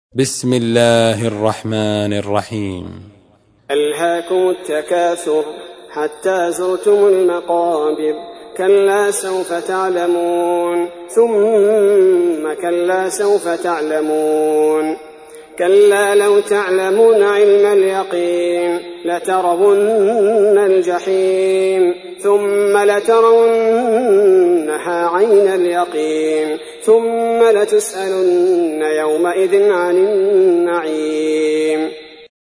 تحميل : 102. سورة التكاثر / القارئ عبد البارئ الثبيتي / القرآن الكريم / موقع يا حسين